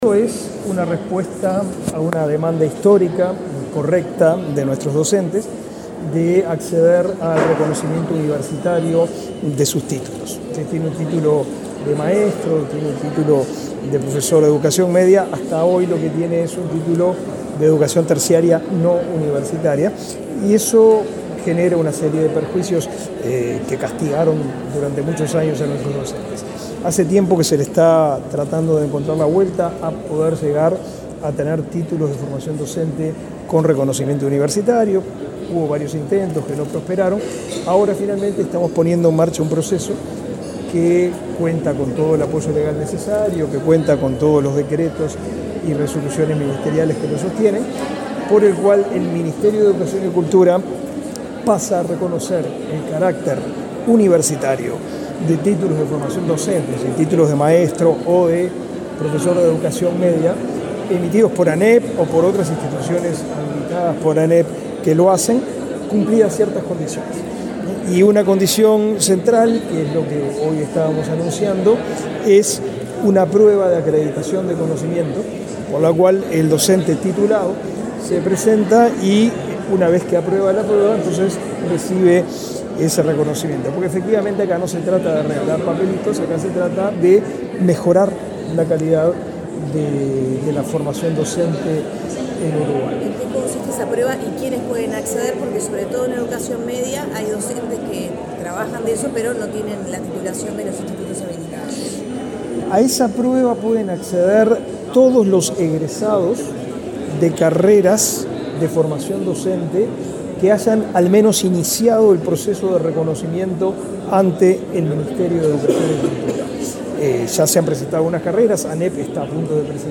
Declaraciones a la prensa del ministro de Educación y Cultura, Pablo da Silveira
Este miércoles 30, el ministro de Educación y Cultura, Pablo da Silveira, dialogó con la prensa, luego de participar, en la Torre Ejecutiva, en el